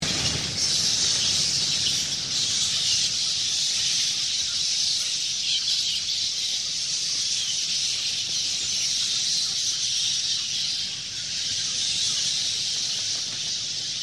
batswarm.mp3